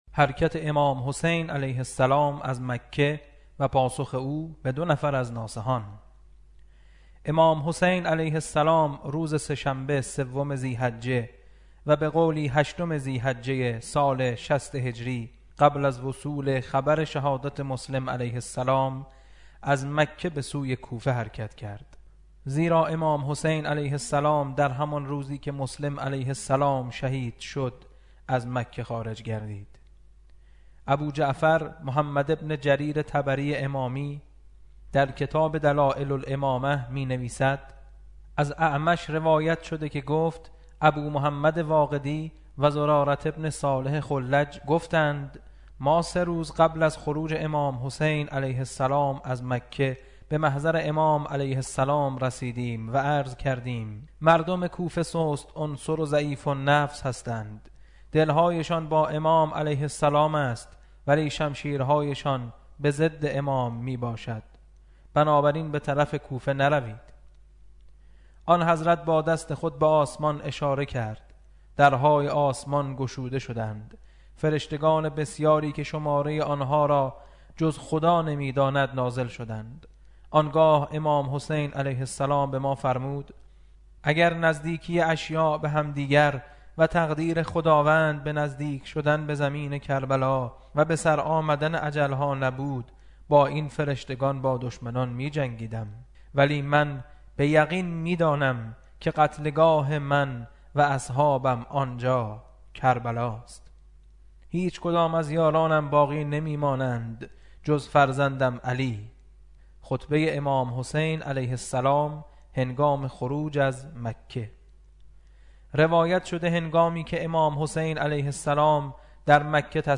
کتاب صوتی